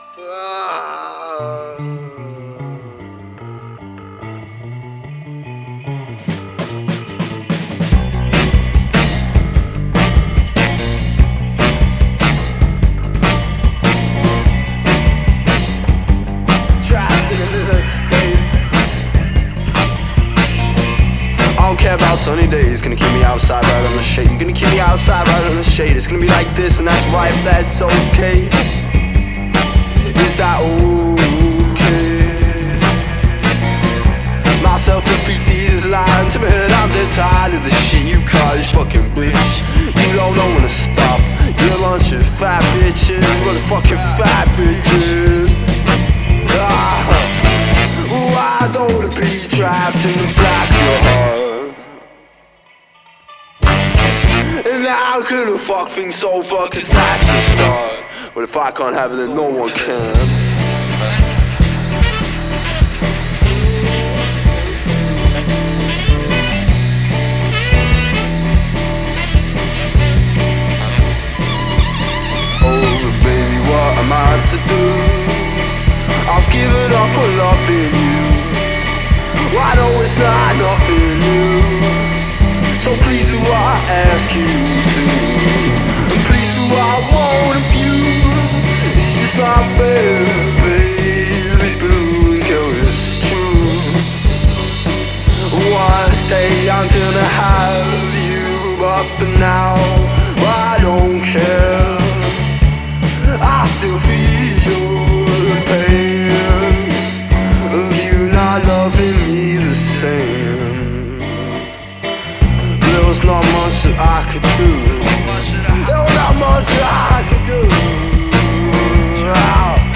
is full of free jazz
with a bit of be bop and hip hop crooning